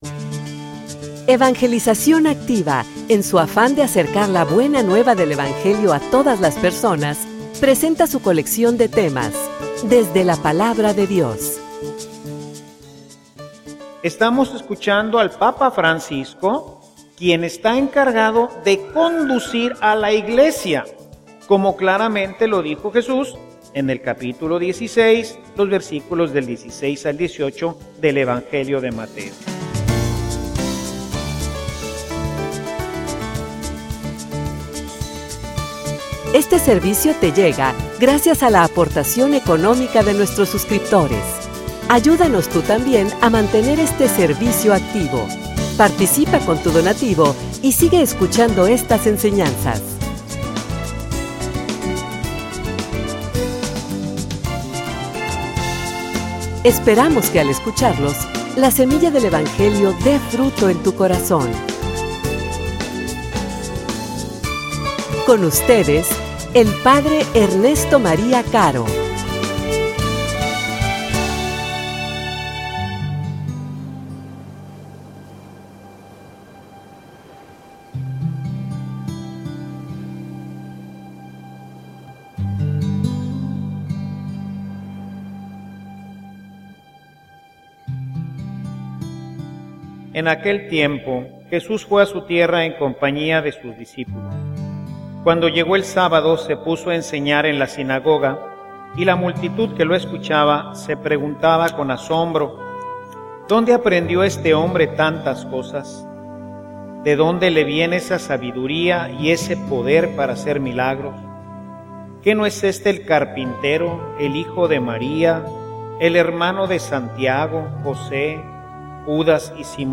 homilia_Quien_los_escucha_a_ustedes.mp3